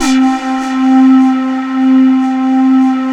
SYNPIPE C3-L.wav